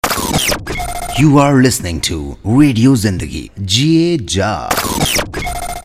Stingers, Bumpers & Station Jingles